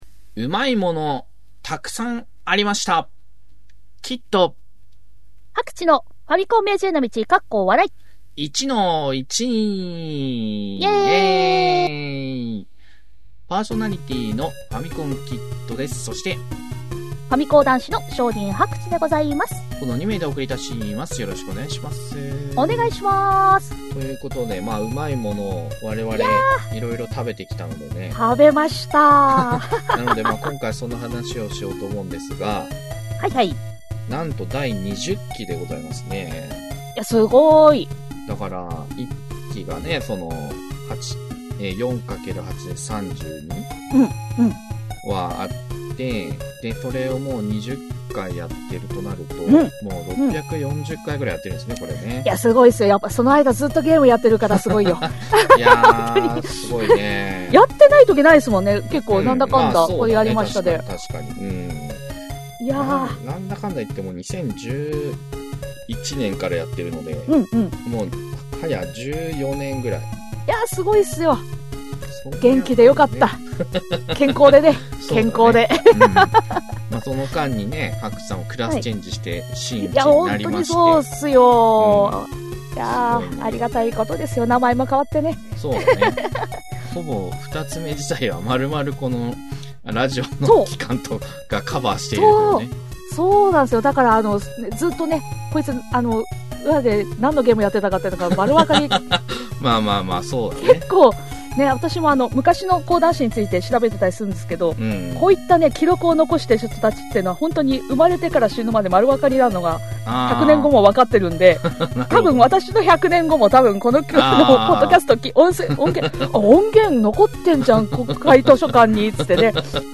昭和っぽいテイストのインターネットラジオ、第20期1-1です！